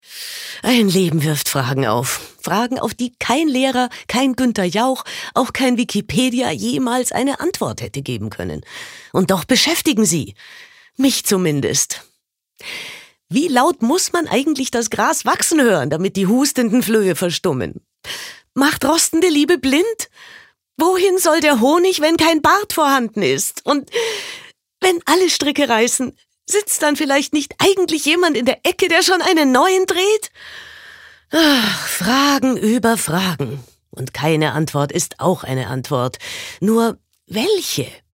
Stimmproben